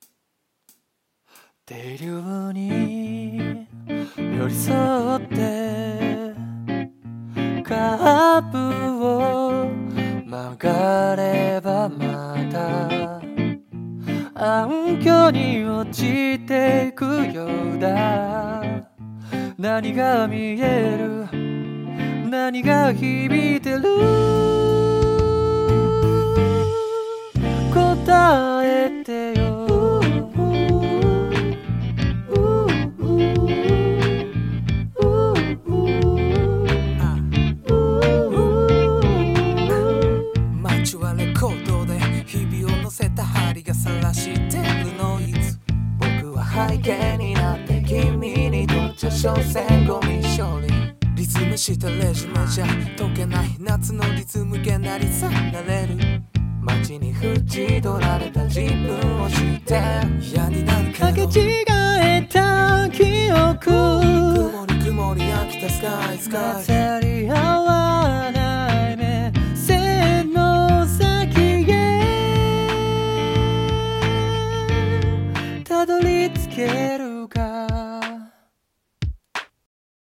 二人声劇【Buddy